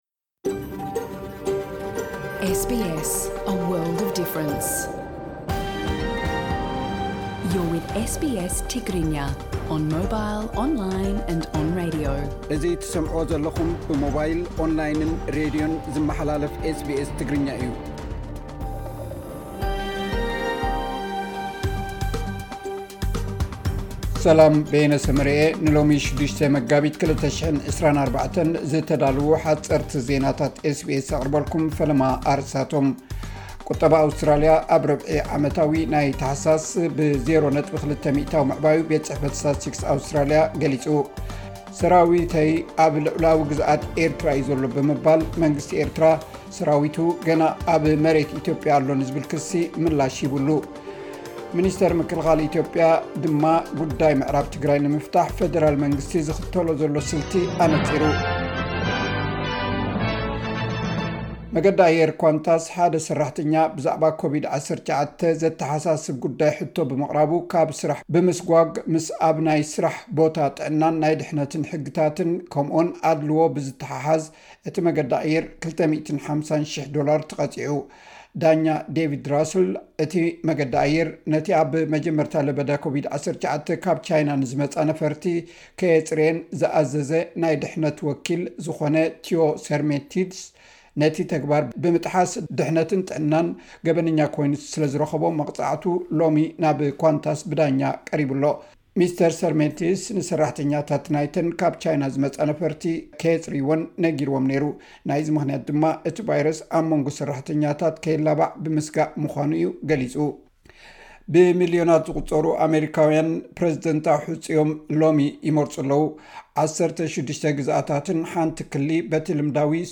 ሓጸርቲ ዜናታት ኤስ ቢ ኤስ ትግርኛ (6 መጋቢት 2024)